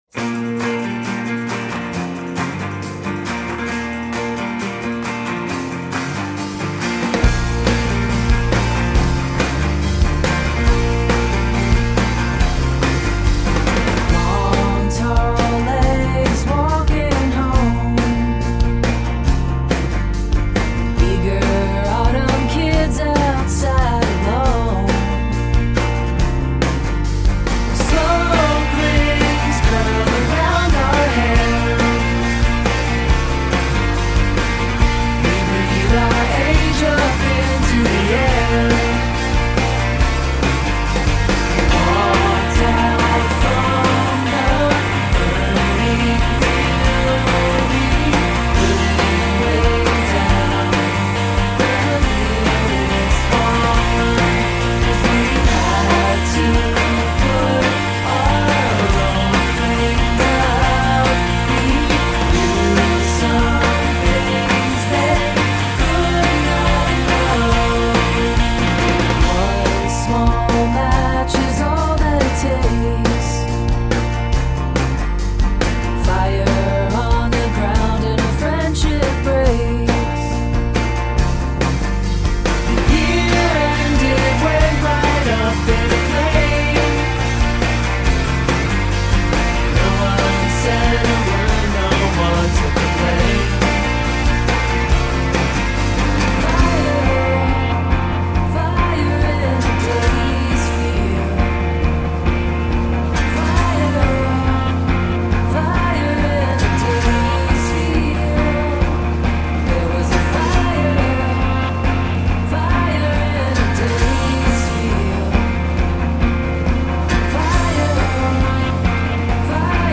Sophisticated rock with emo undercurrents.